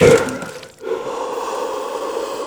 BREATH    -L.wav